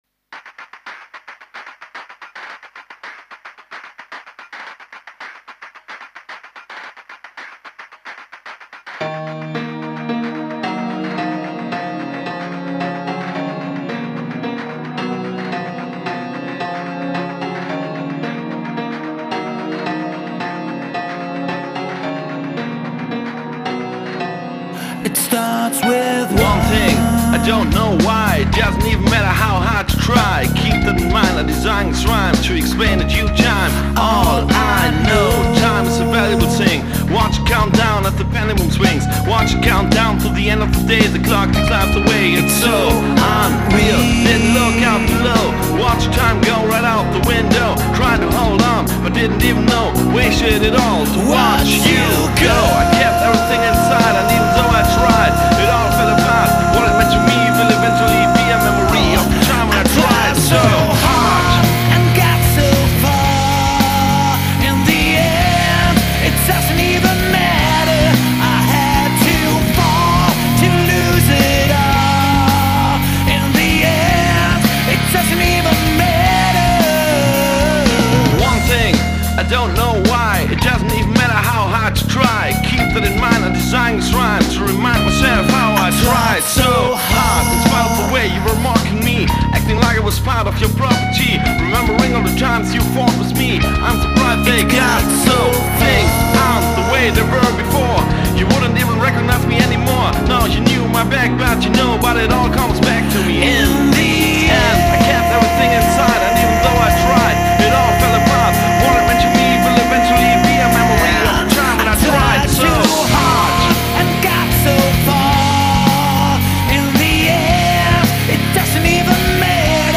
Rock-Coverband
die drei verschiedenen Frontgesänge